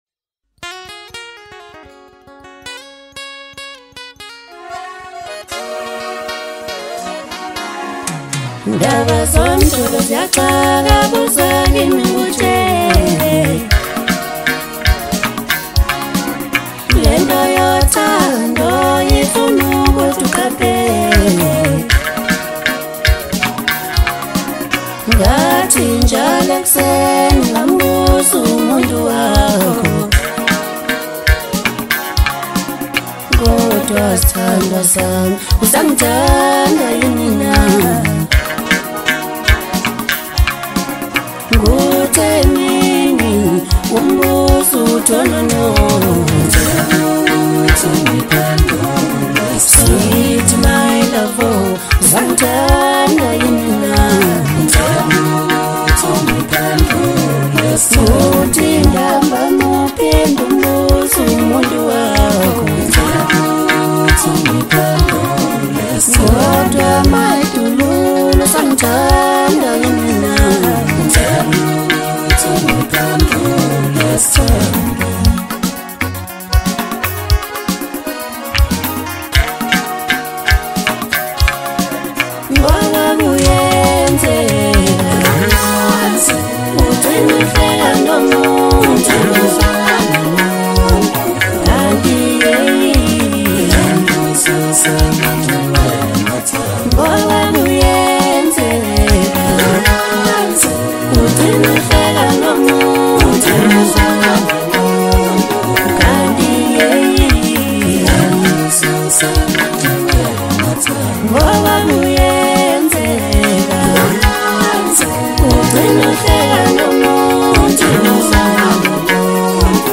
Home » Hip Hop » Latest Mix » Maskandi